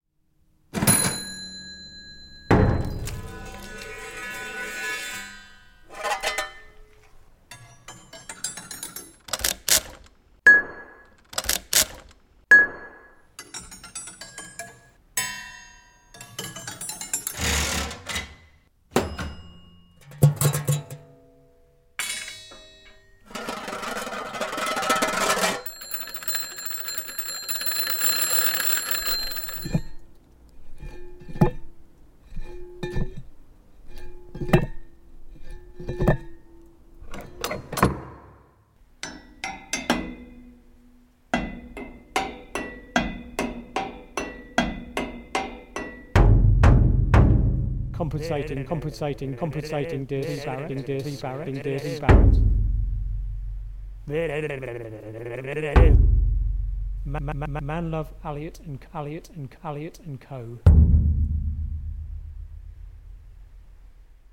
Cambridge Museum of Technology - short found sounds sequence.wav
A variety of sounds recorded at the Cambridge Museum of Technology